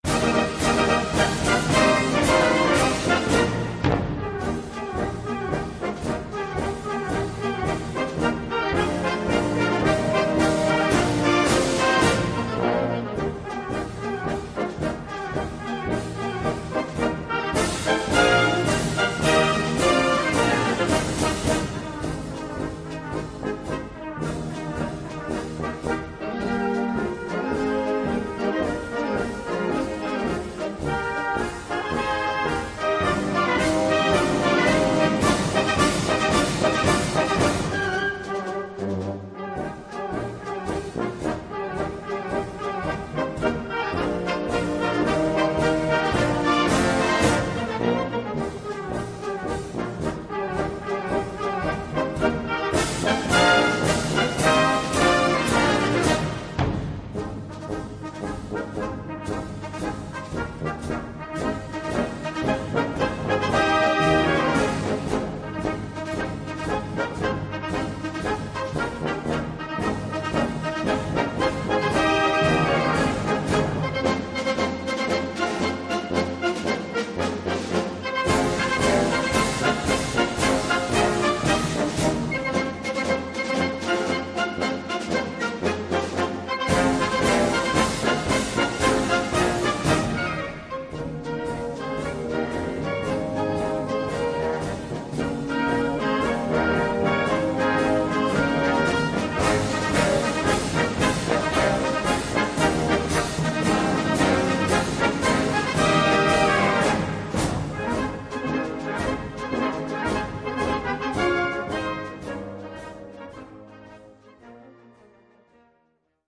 Gattung: Marsch-Medley
Besetzung: Blasorchester